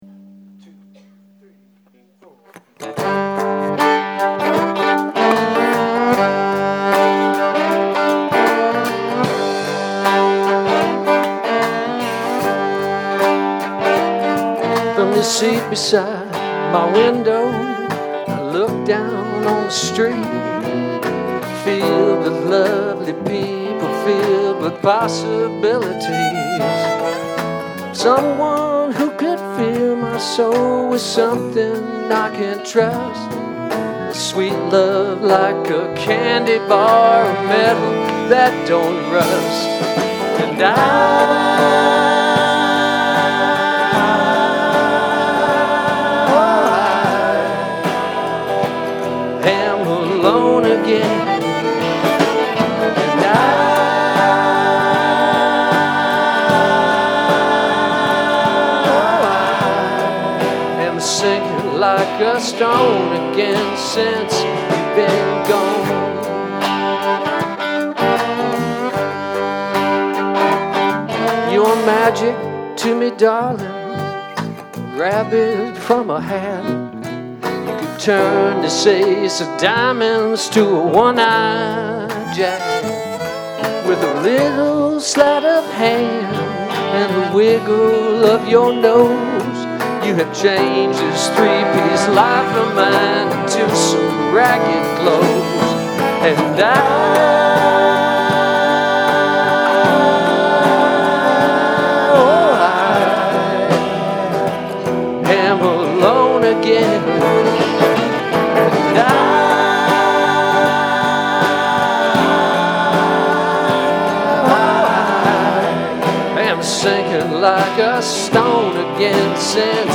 Soundboard audio